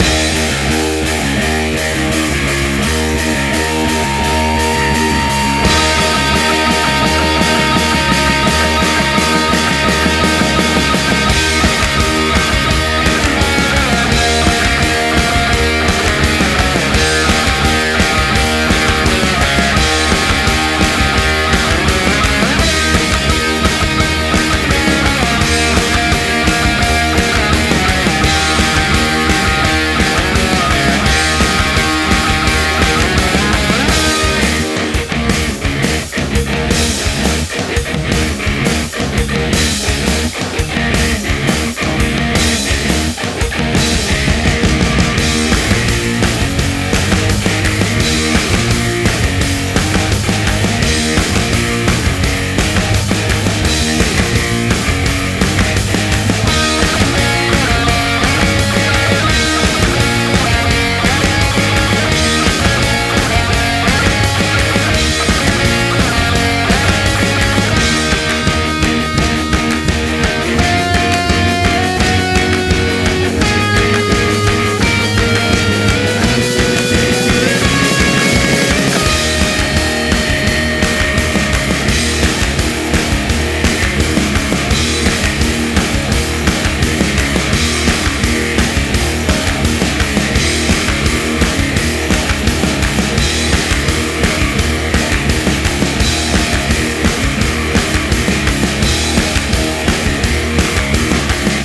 Rock_Theme
Dynamic, almost positive and looped theme ;)
Rock_Theme.ogg